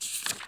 x_enchanting_scroll.6.ogg